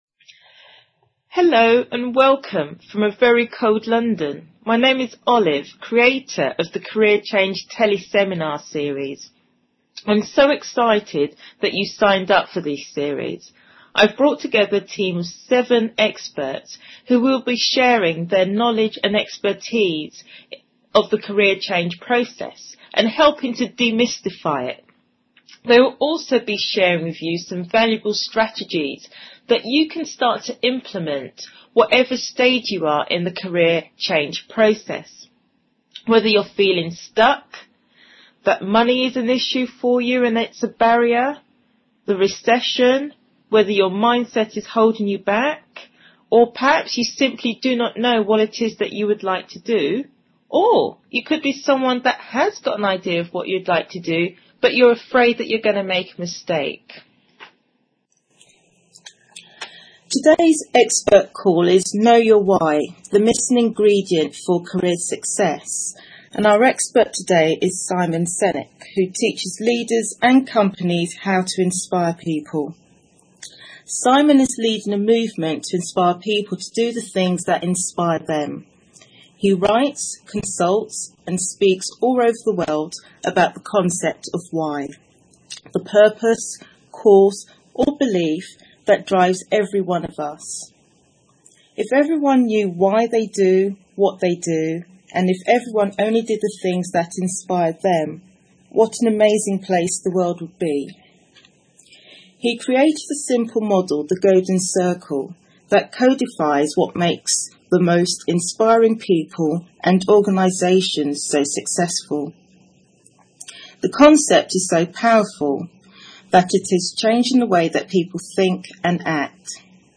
Teleseminar Audio - Simon Sinek (.mp3) 4Mb